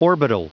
Prononciation du mot orbital en anglais (fichier audio)
Prononciation du mot : orbital